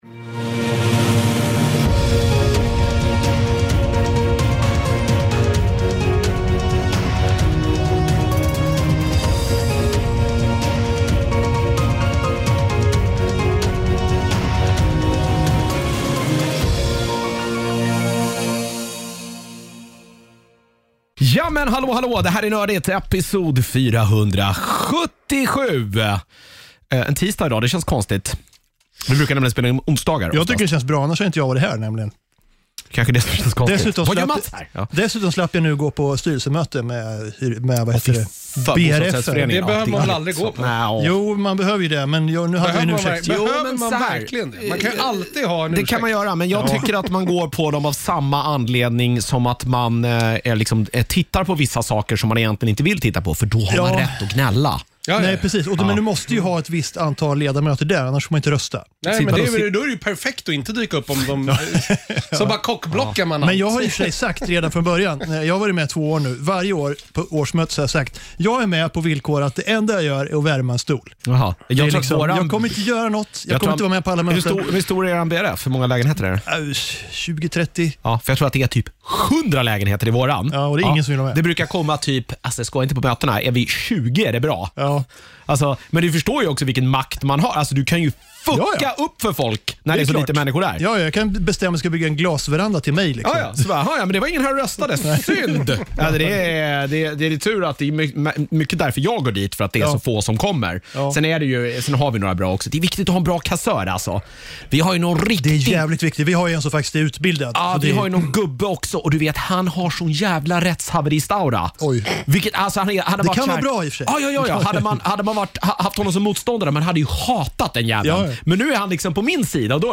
Vi är lite fler än vanligt den här veckan, när fyra personer samlas i studion!